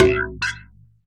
Perc 6.wav